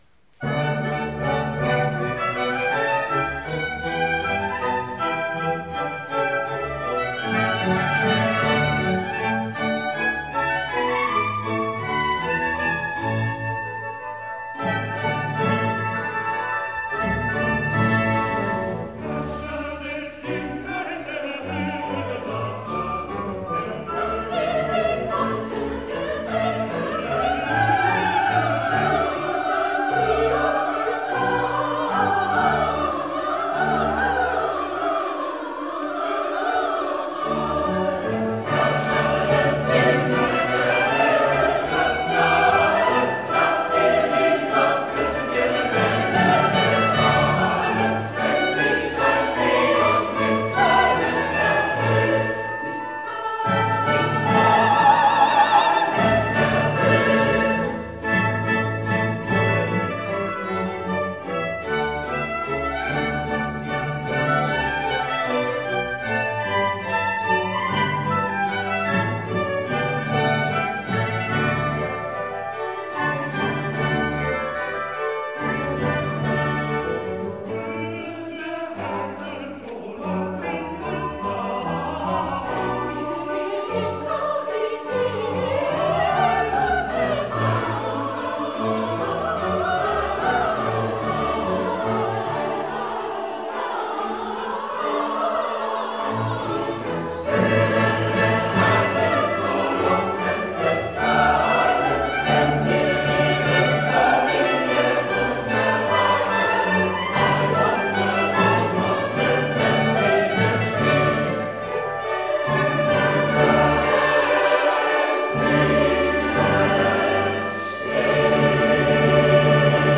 Anspruchsvolle Chormusik – seit über 40 Jahren
Diese Mitschnitte haben zwar nur eine eingeschränkte Qualität, ergeben aber einen guten Eindruck von der Vielfältigkeit unseres Repertoires.
Weihnachtskonzert 2025